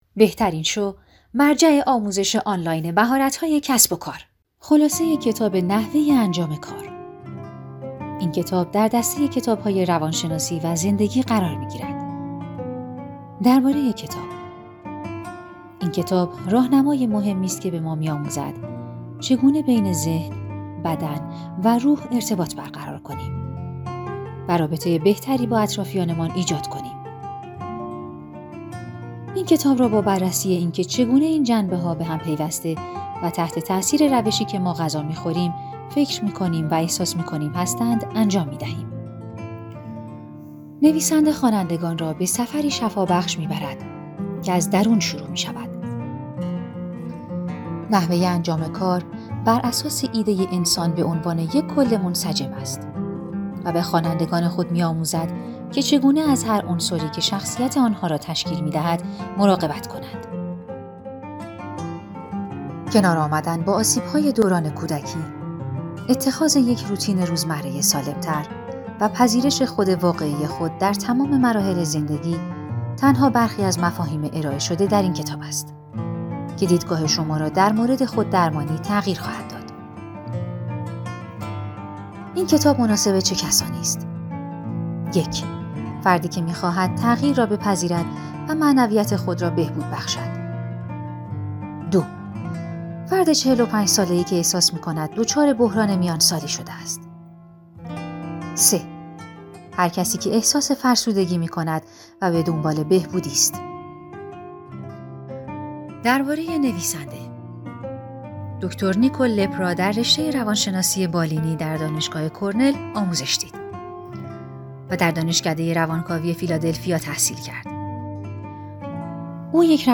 کتاب صوتی موجود است